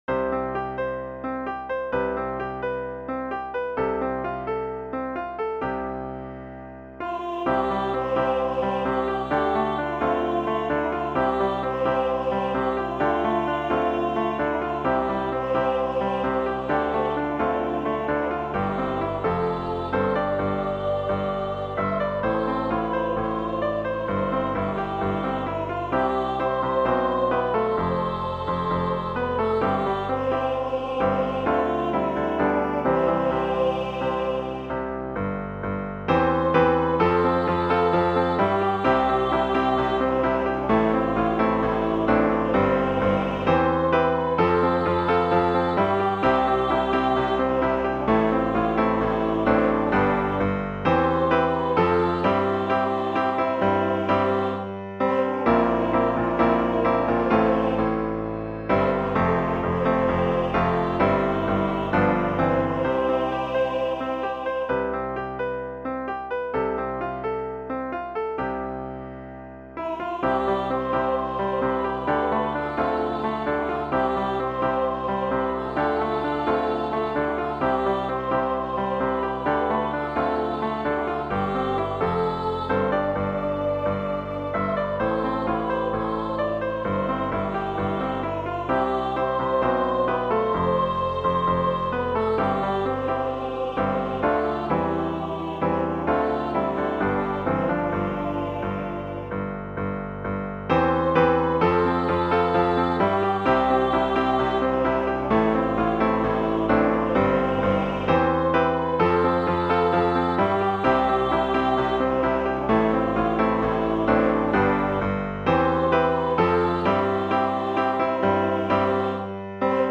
2 part choir, Young Women Voices